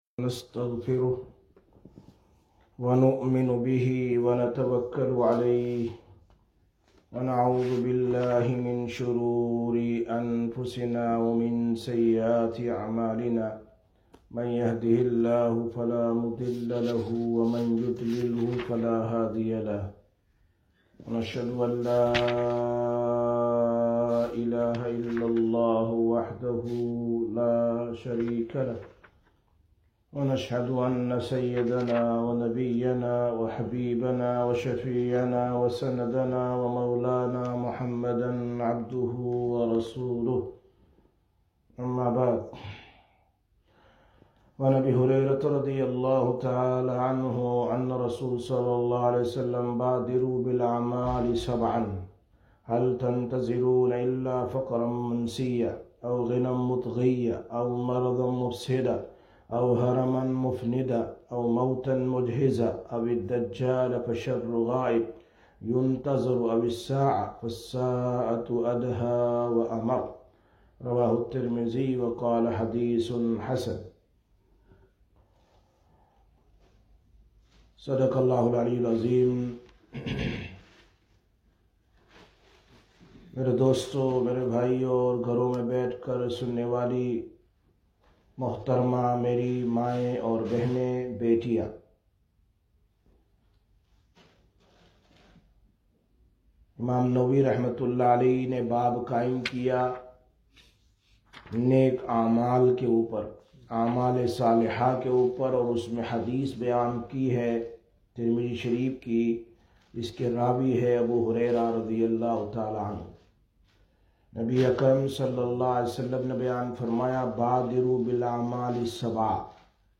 23/06/2021 Sisters Bayan, Masjid Quba